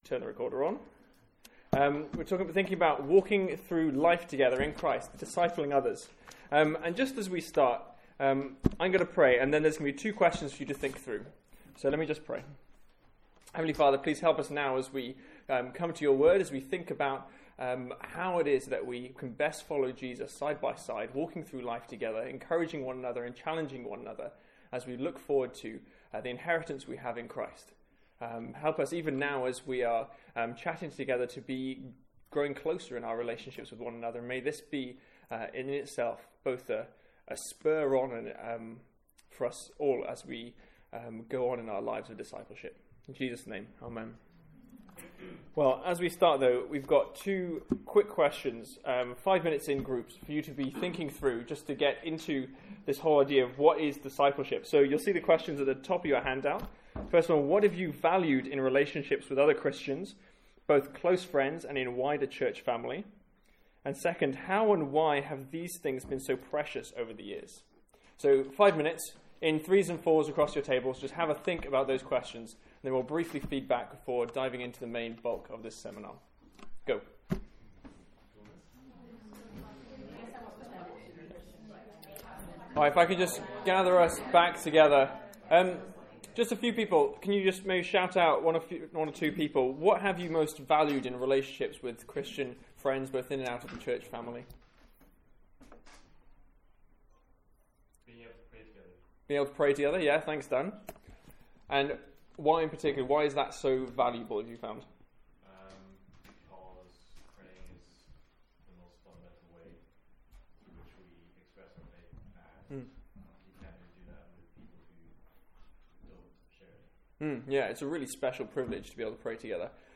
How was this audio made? From our final student lunch of the academic year. Note – Group discussion editted out and question time begins at 35min.